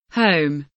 home kelimesinin anlamı, resimli anlatımı ve sesli okunuşu